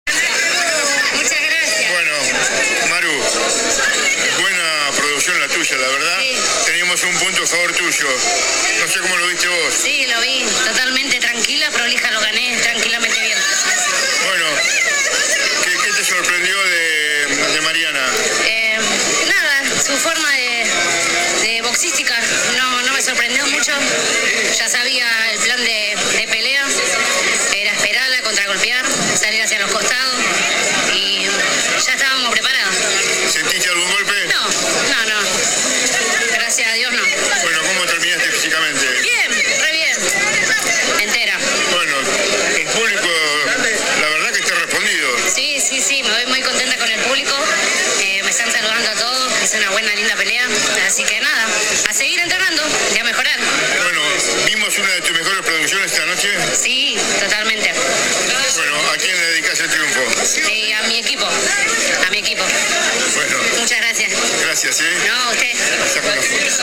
En el final  de la noche abordamos a la deportista visitante.
AUDIO DE LA ENTREVISTA